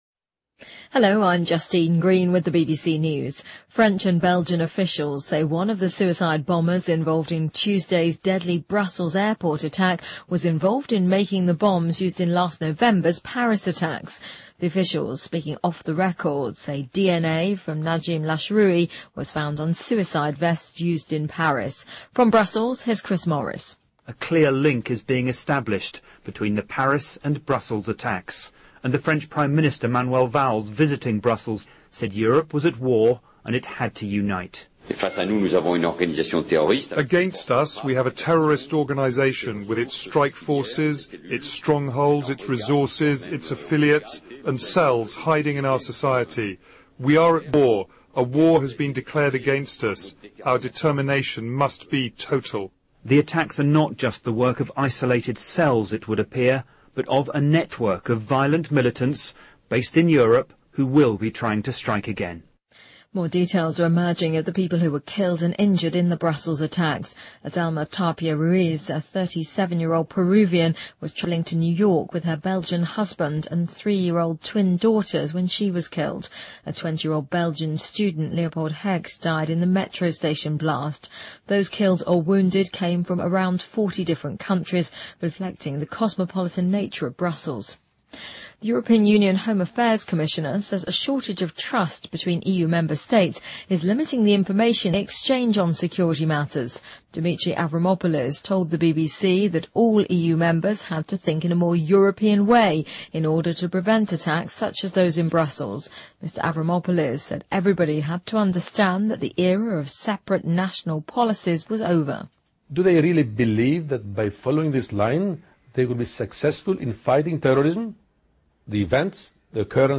BBC news,新西兰举行公投决定是否将国旗改为银蕨星旗